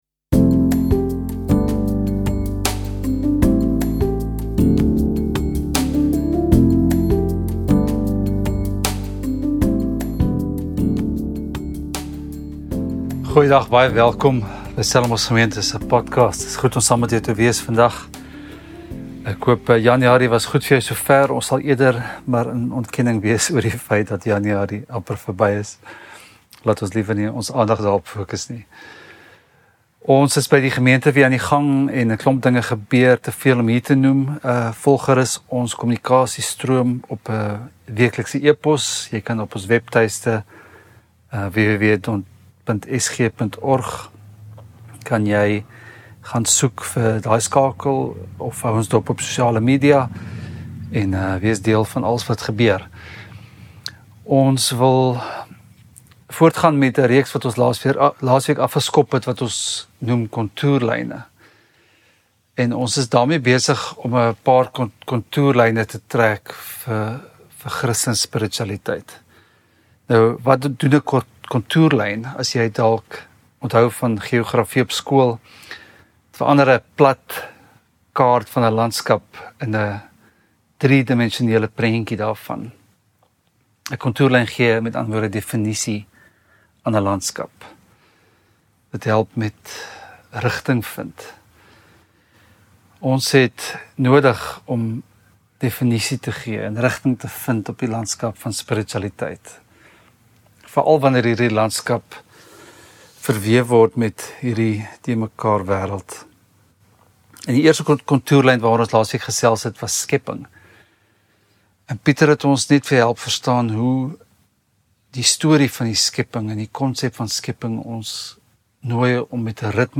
Preke